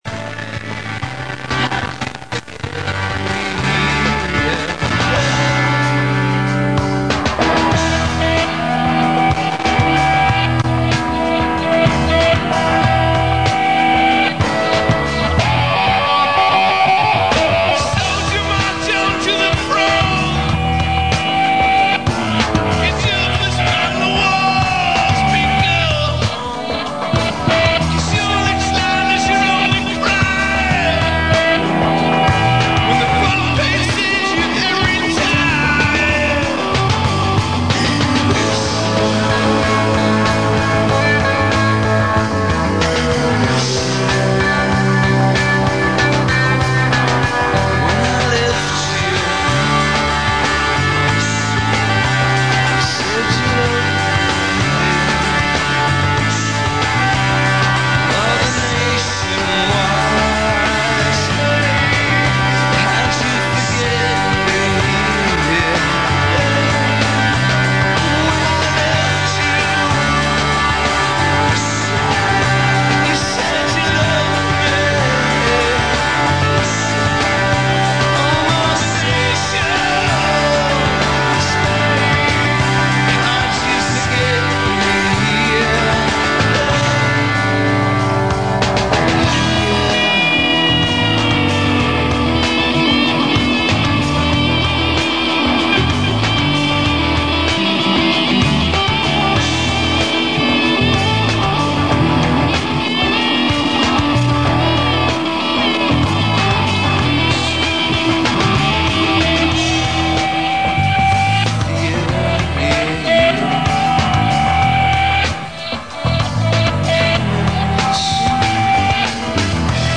• I recorded this onto a cassette in March of 93.
• The song sounds like it was produced in a studio.